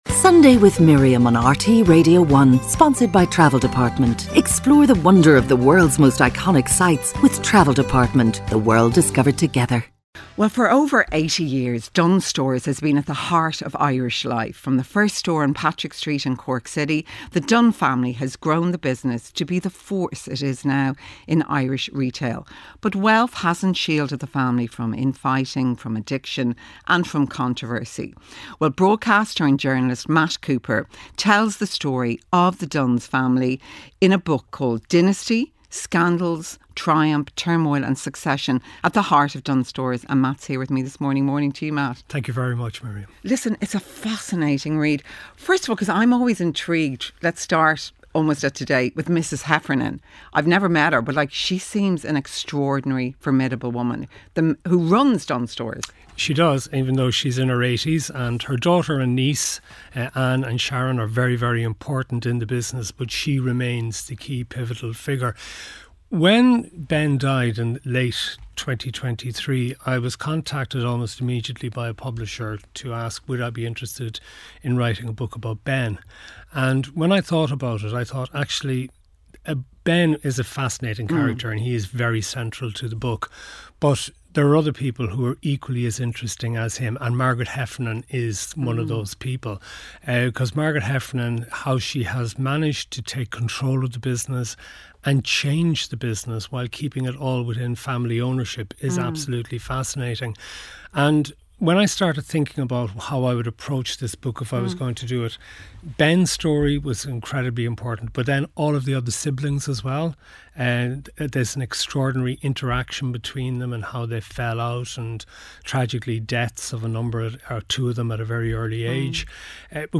Journalist Matt Cooper joined Miriam in studio to discuss the powerful family behind Dunnes Stores — charting their rise in Irish retail, their wealth, their resilience, and the controversies that shaped them - which is all charted in his new book 'Dynasty (published by Eriu)